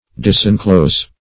Disenclose \Dis`en*close\, v. t.